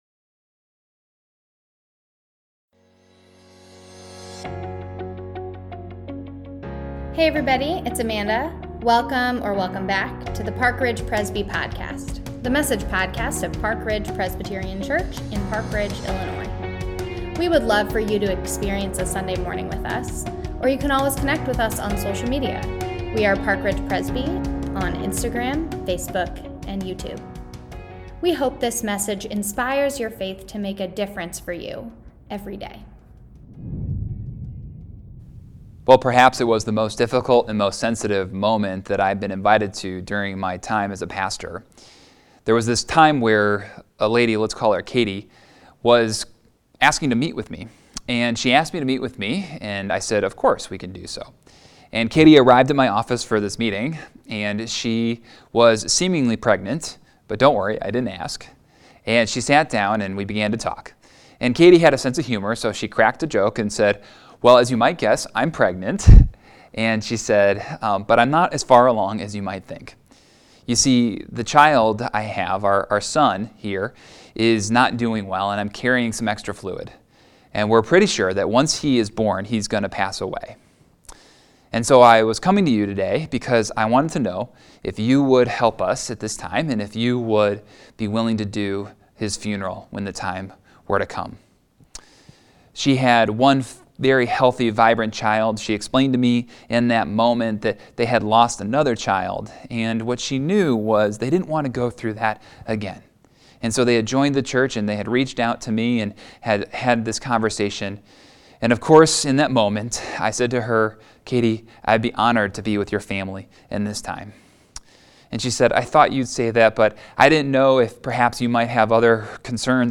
Service of Remembrance | Sunday October 31st 2021